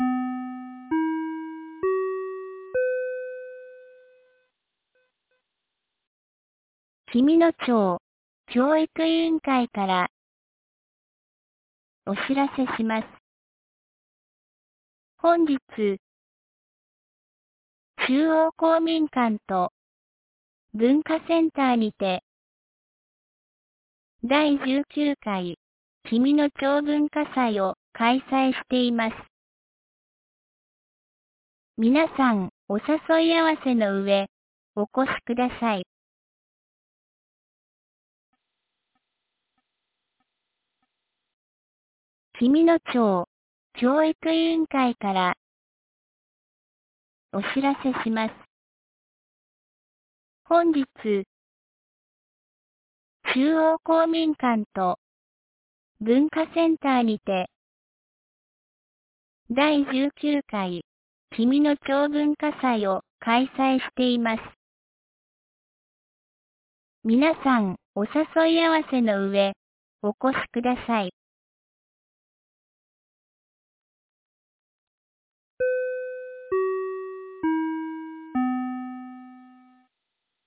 2024年11月03日 12時36分に、紀美野町より全地区へ放送がありました。